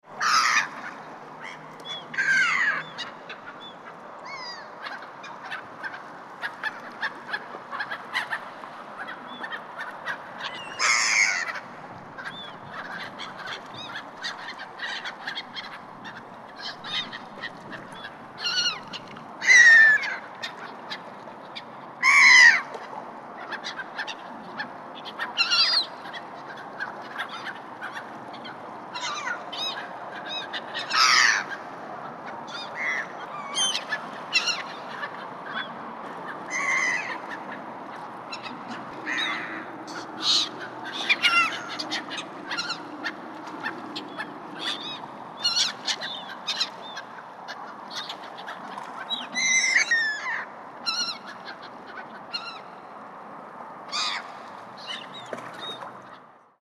جلوه های صوتی
دانلود صدای مرغ دریایی از ساعد نیوز با لینک مستقیم و کیفیت بالا
برچسب: دانلود آهنگ های افکت صوتی انسان و موجودات زنده